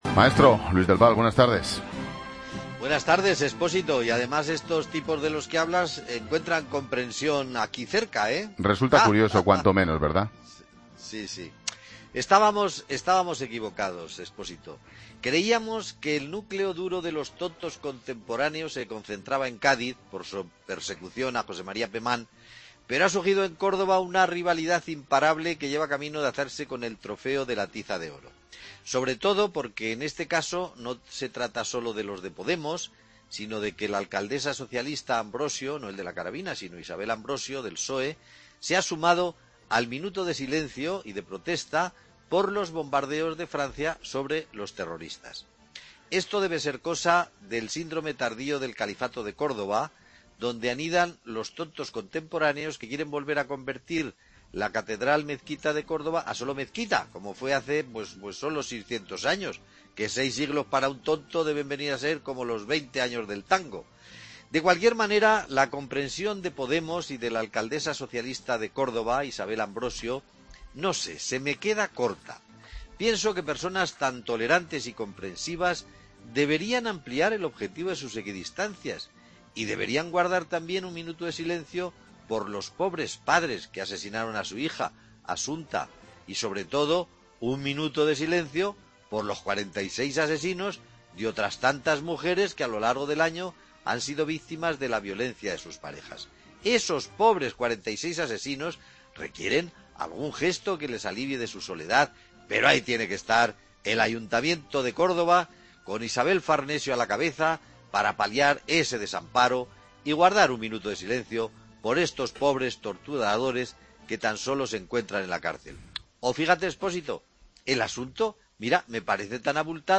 Luis Del Val, el sobrino de la tía Pascualina, dedica su comentario a Isabel Ambrosio, la alcaldesa de Córdoba, que también pide un minuto de silencio tras los bombardeos de Francia a objetivos yihadistas.